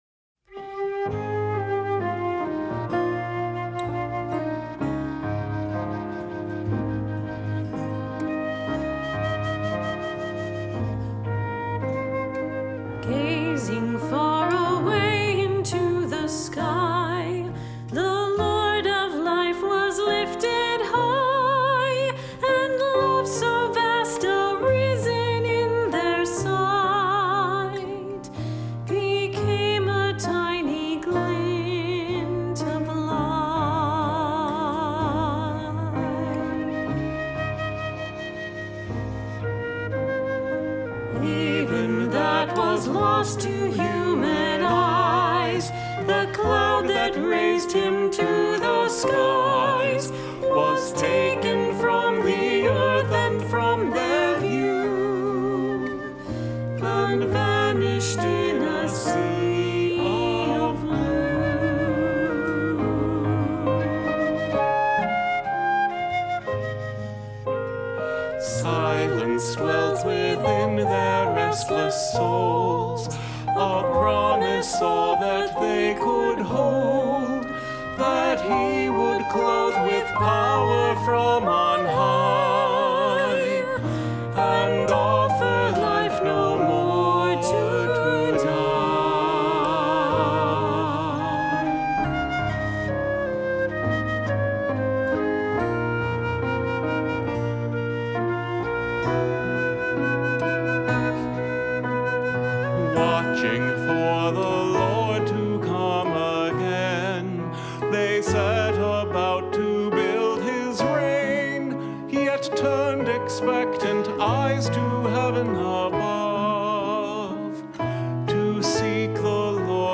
Voicing: Two-part mixed